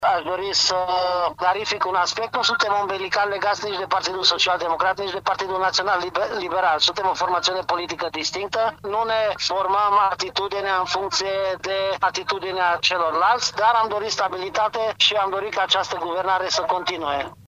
Purtătorul de cuvânt al UDMR: Nu suntem legați de niciun partid